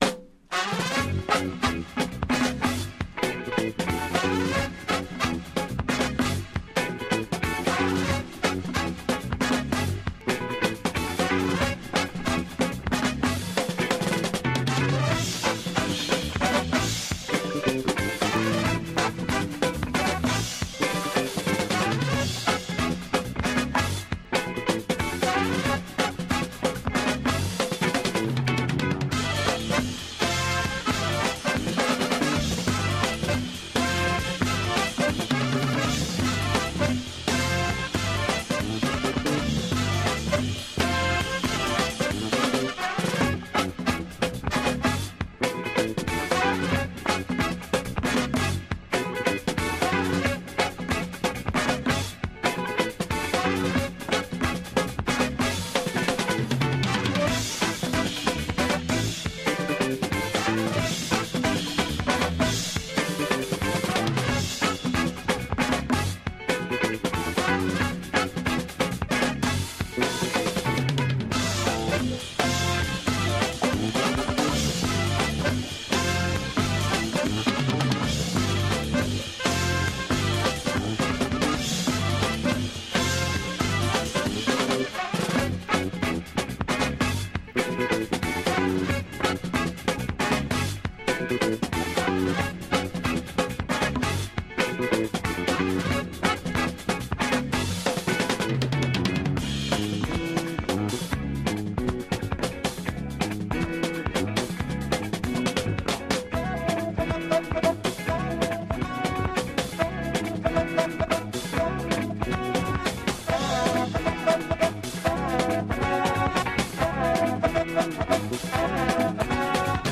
Wild !